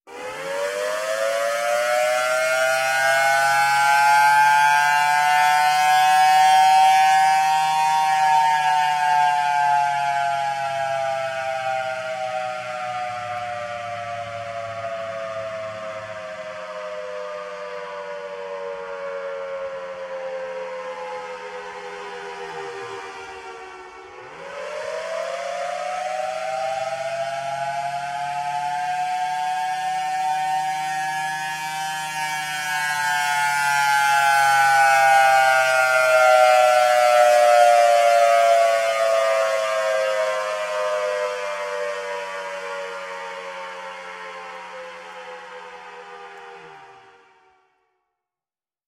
звук сирены бомбардировки